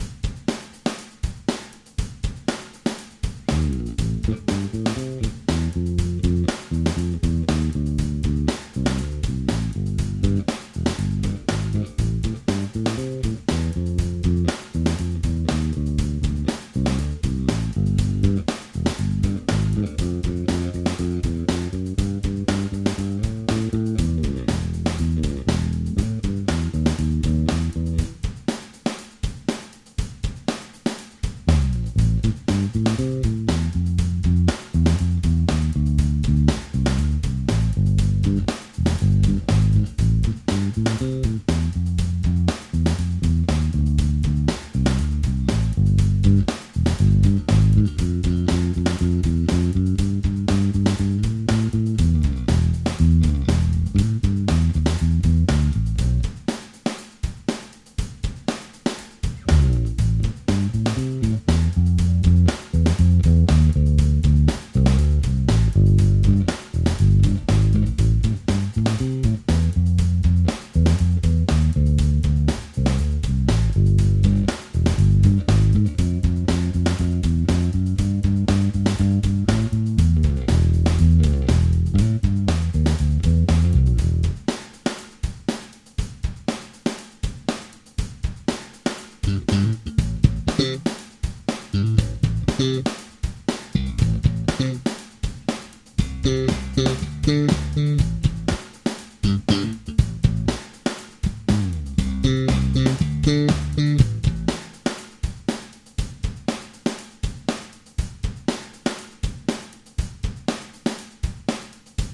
Fokus Jazz Bass
Oba nástroje mají na sobě skoro rok staré struny Daddario nickel wound .45. Pokud by měl někdo pochybnosti - nastavení mixu je stejné. Fokus má slabší snímače a tedy i výstup.